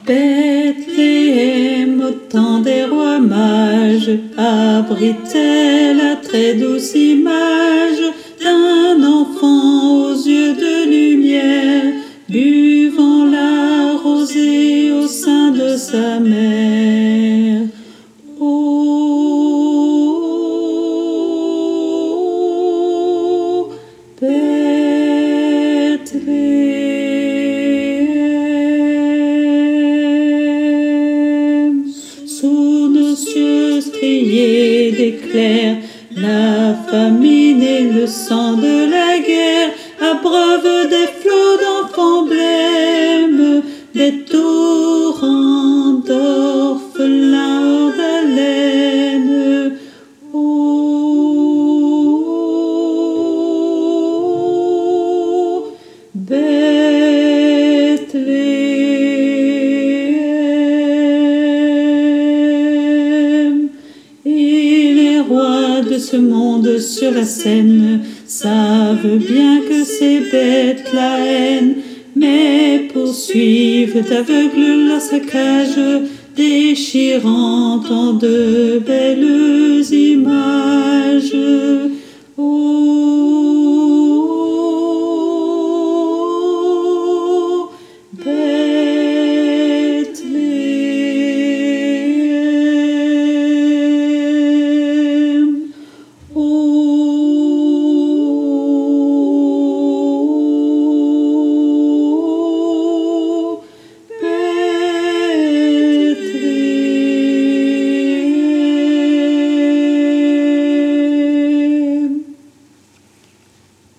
Mp3 versions chantées
Alto Et Autres Voix En Arriere Plan